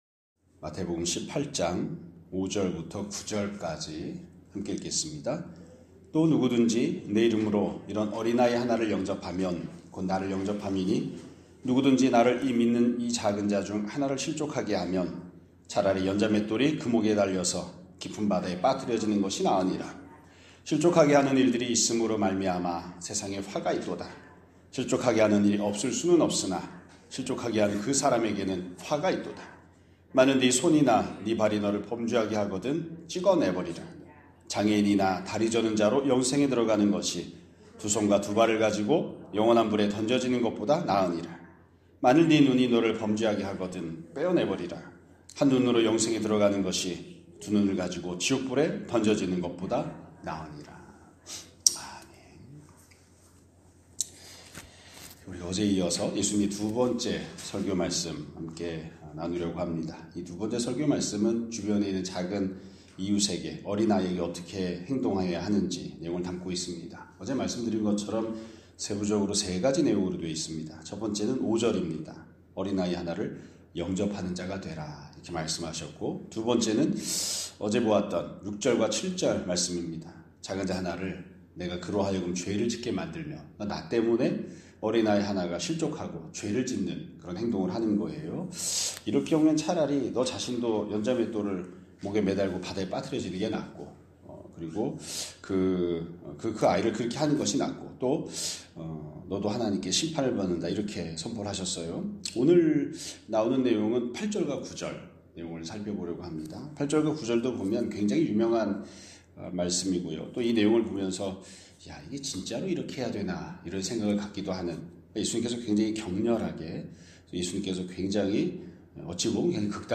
2025년 12월 5일 (금요일) <아침예배> 설교입니다.